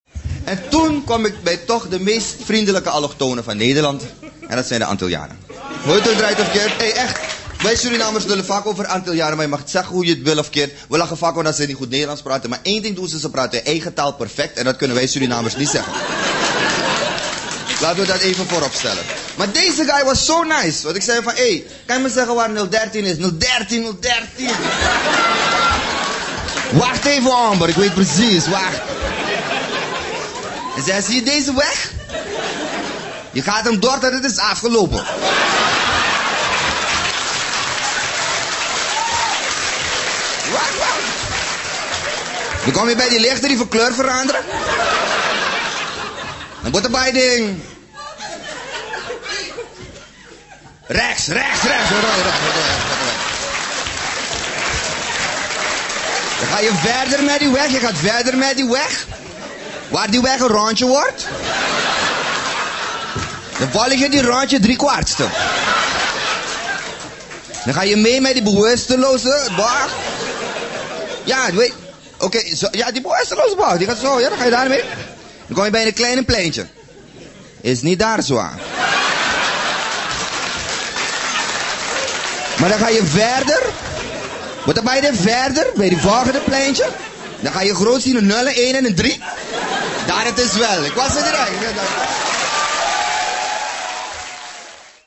Drie fragmenten uit shows.